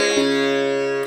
SITAR GRV 17.wav